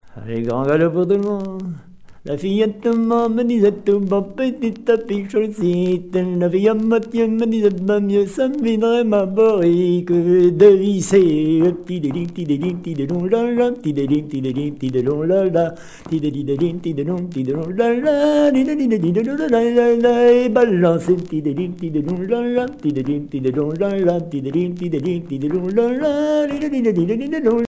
Mémoires et Patrimoines vivants - RaddO est une base de données d'archives iconographiques et sonores.
Quadrille de Candé turluté - Grand galop